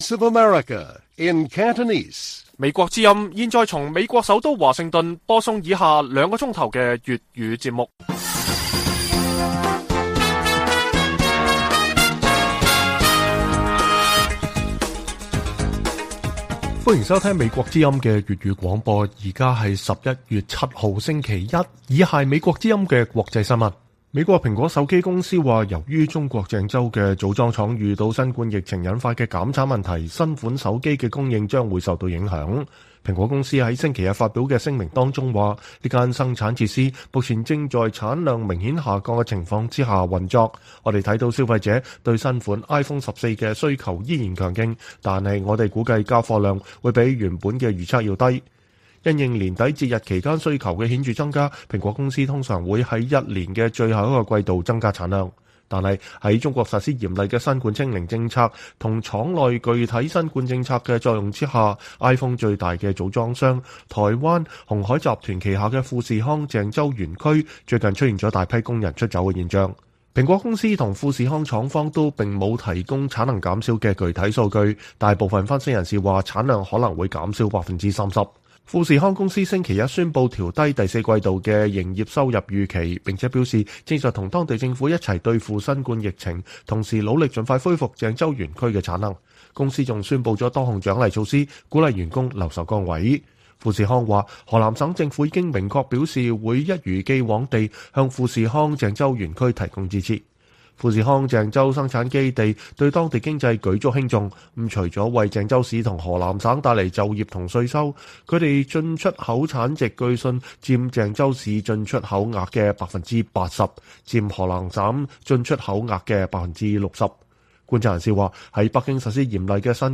粵語新聞 晚上9-10點: 受鄭州廠區影響 蘋果公司調低手機供應預期